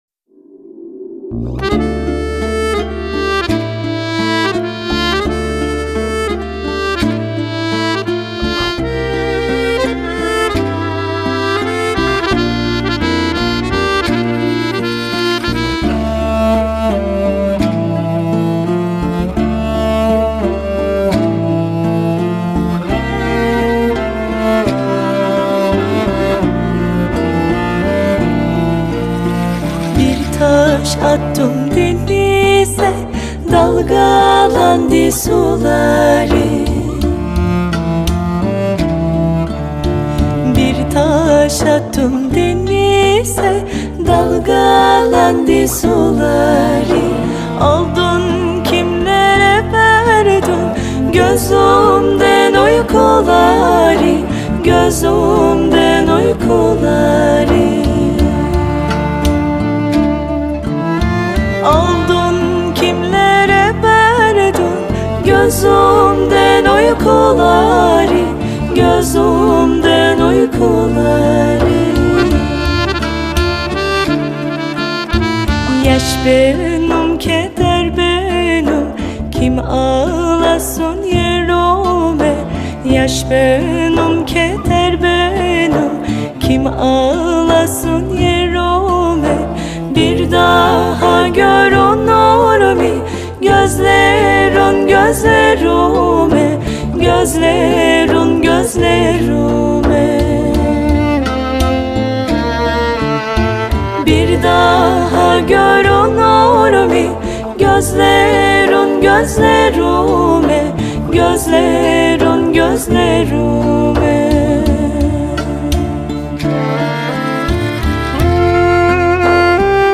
Kara Deniz Sarkilari